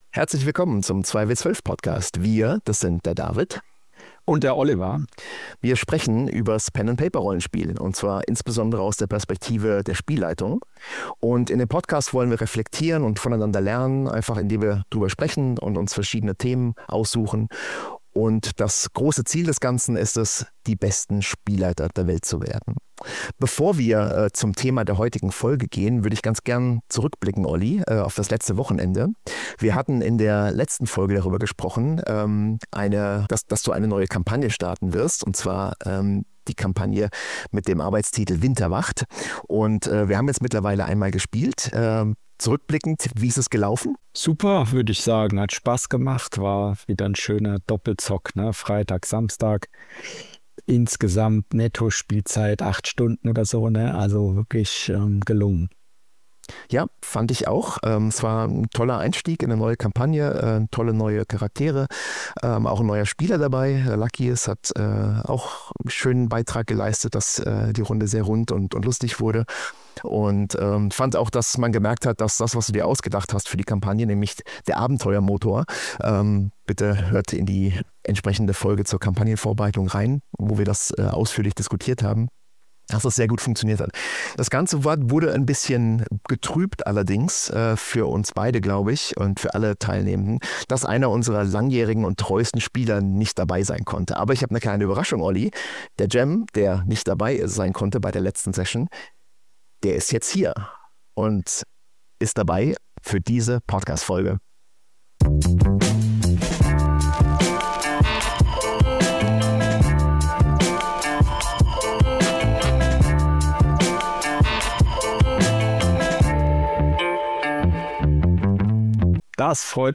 Spielerinterviews – Der Schauspieler (Ep. 20) ~ 2W12 - Die besten Spielleiter der Welt Podcast
Diese Folge ist deshalb mehr als nur ein Interview.